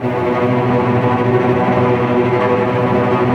Index of /90_sSampleCDs/Roland - String Master Series/STR_Vcs Tremolo/STR_Vcs Trem f